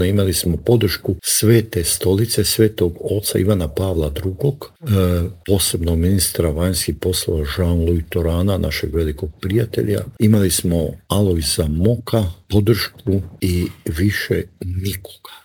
Cilj je bio eliminirati predsjednika Franju Tuđmana, a tog se dana u Intervjuu tjedna Media servisa prisjetio bivši ministar vanjskih poslova Mate Granić.